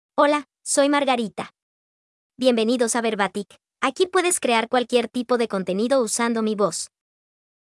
Margarita — Female Spanish (Panama) AI Voice | TTS, Voice Cloning & Video | Verbatik AI
Margarita is a female AI voice for Spanish (Panama).
Voice sample
Listen to Margarita's female Spanish voice.
Margarita delivers clear pronunciation with authentic Panama Spanish intonation, making your content sound professionally produced.